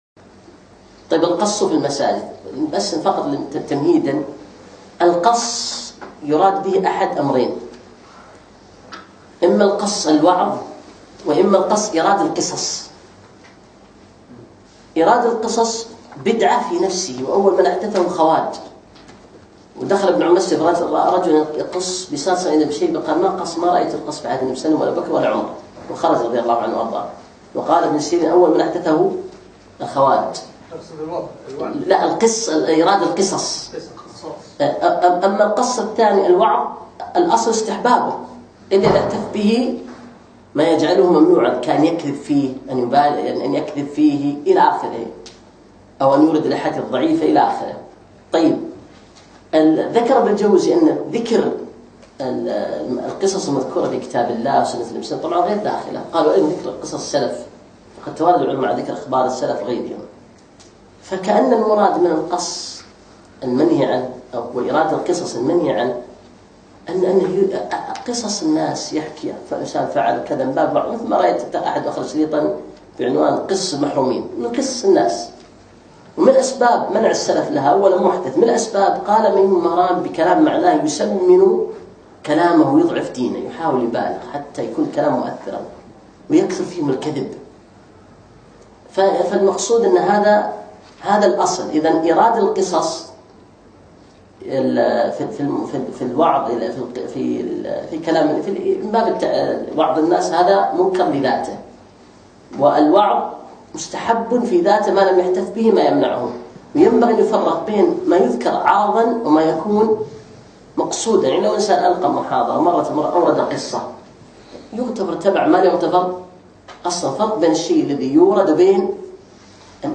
يوم الاربعاء 11 شوال 1438 الموافق 5 7 2017 في ديوانية مشروع الخالص سعد العبدالله
الدرس الرابع والأخير